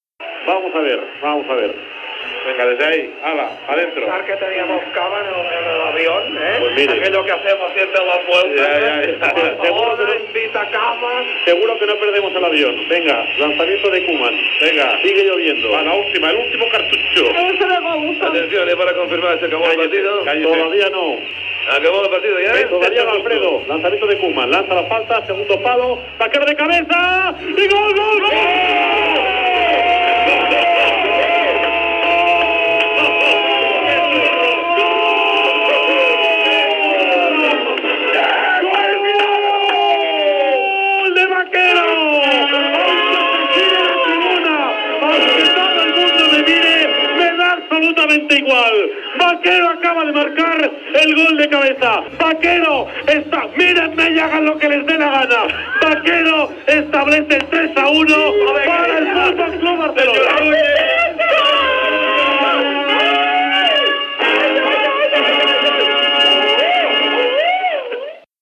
Narració del gol de José Mari Bakero al Fritz Walter Stadion de Kaiserslautern, als segons finals del partit de vuitens de final entre el Kaiserslautern i el FC Barcelona que permetia al Barça passar l'eliminatòria.
Esportiu